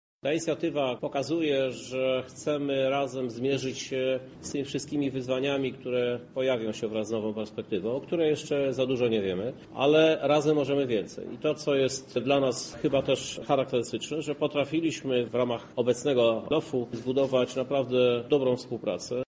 Chcemy wyeliminować obszary zdegradowane społecznie i gospodarczo, wspierać też tereny cenne przyrodniczo czy kulturowo – mówi prezydent Lublina Krzysztof Żuk: